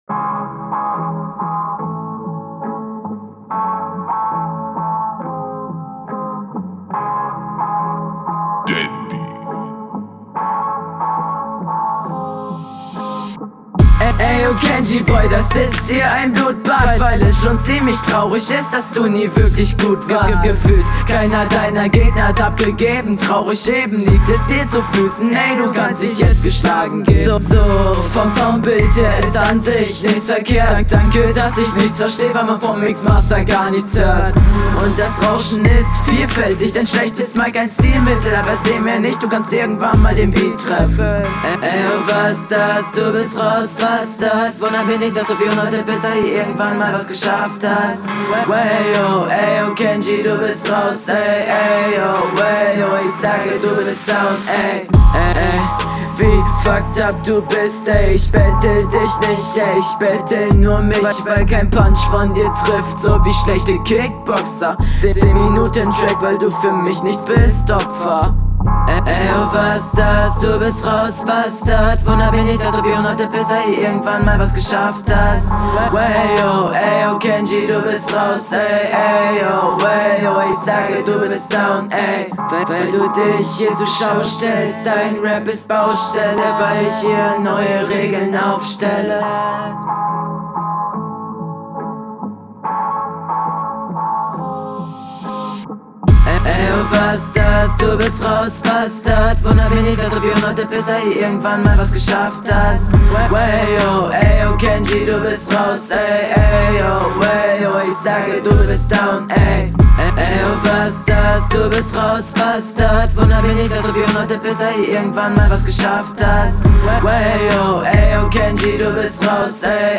Fangen wir mal an, yes yes yo, Beat ist cool, relativ generic trap 808 type …
Interessanter Beat. ey eyo Einstieg kommt cool, kommst direkt mit gutem Stimmdruck rein.
Finde deinen Einstieg recht fresh.